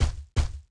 walk_act_1.wav